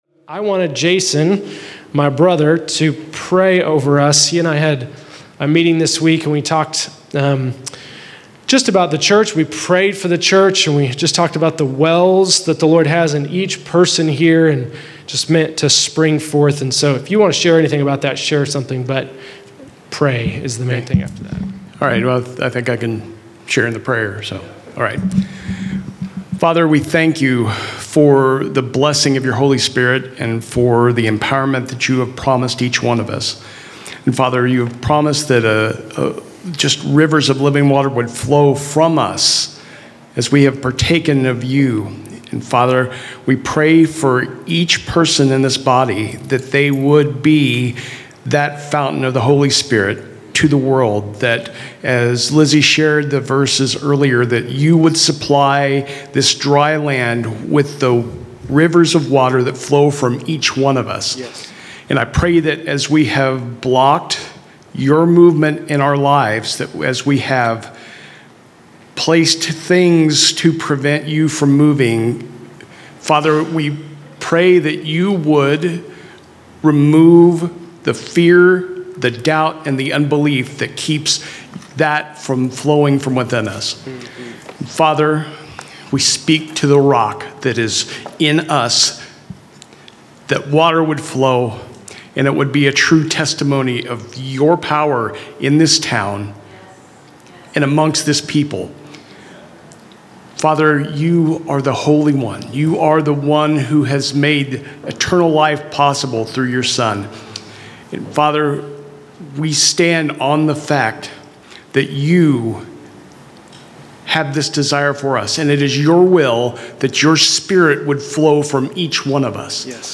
Exhortation :